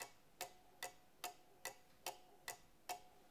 cuckoo_tick.L.wav